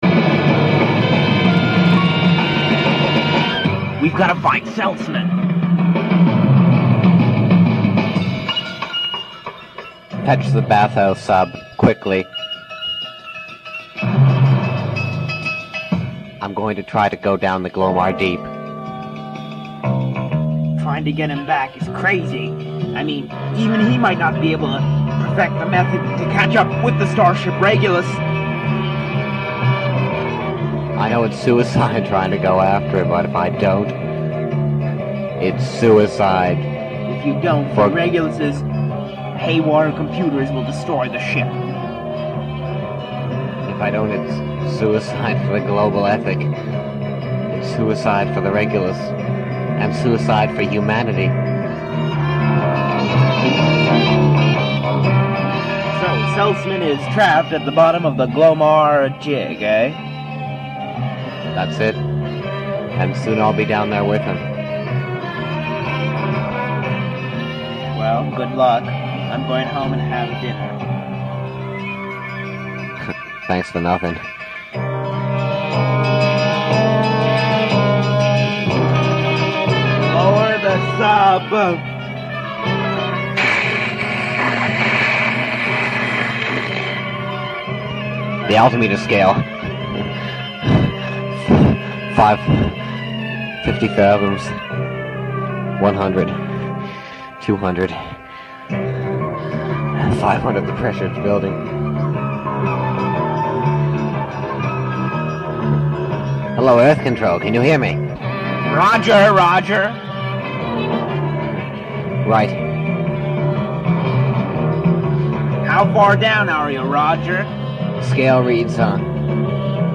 Lifting its premise from any number of sci-fi novels, it tells the story of the starship Regulus, sent on a 50-year journey to the star Vega in the hope of finding more elbow room for an overpopulated earth. Already, this was more of a premise than most of our tape recorder plays had.